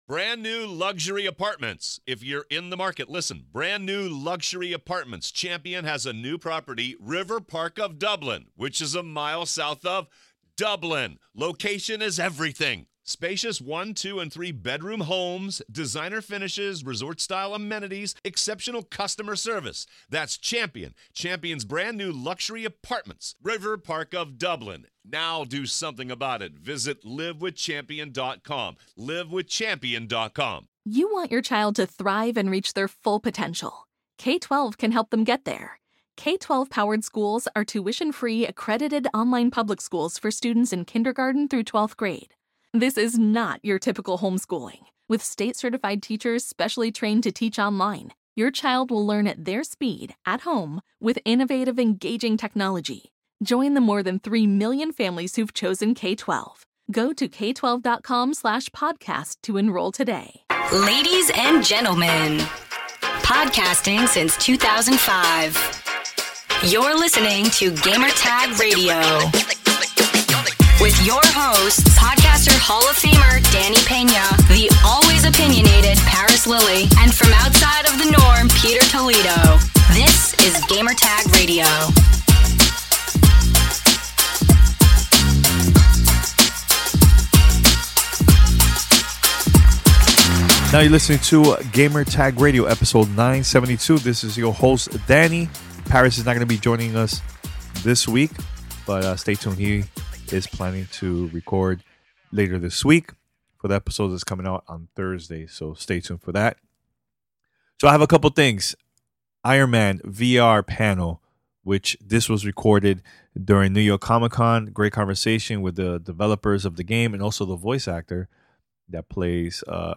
Iron Man VR panel from New York Comic Con.